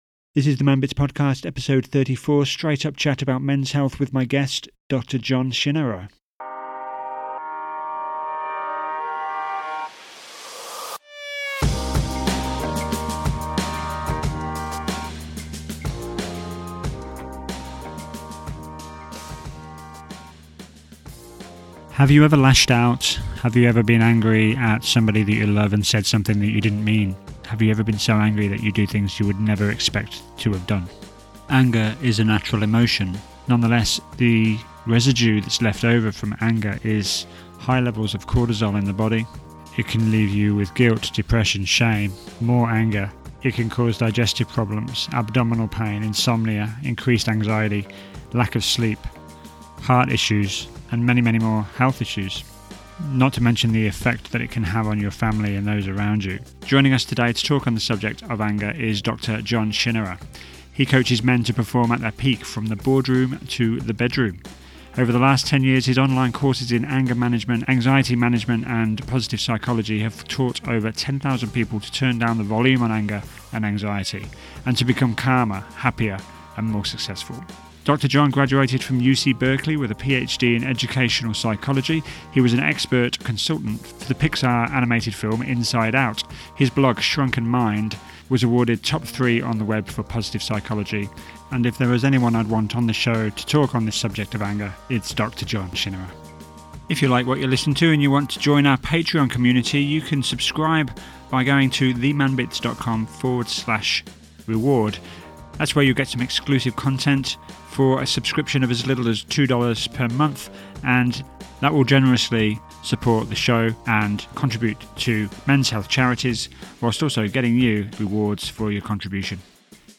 What Are the Best Ways to Reduce Anger? Interview